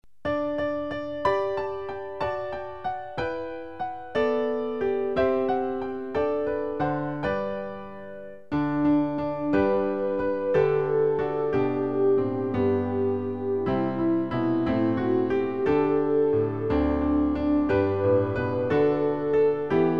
Klavier-Playback zur Begleitung der Gemeinde
MP3 Download (ohne Gesang)
Strophen: 3